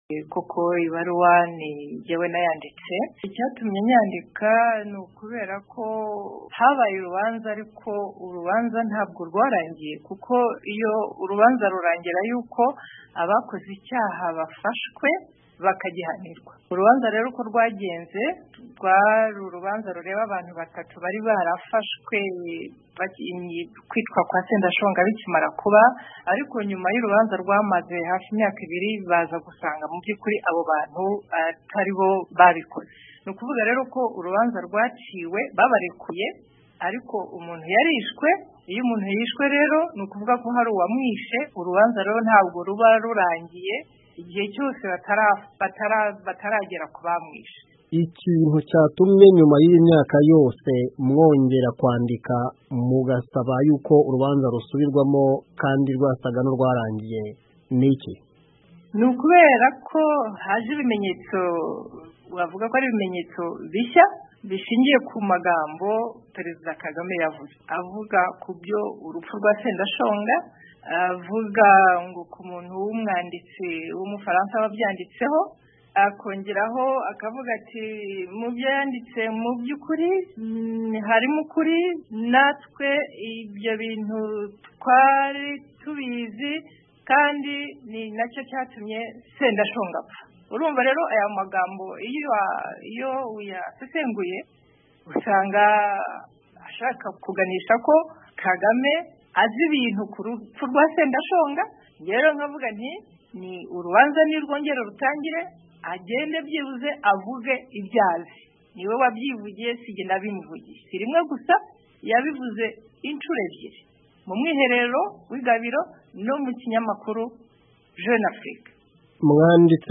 Ikiganiro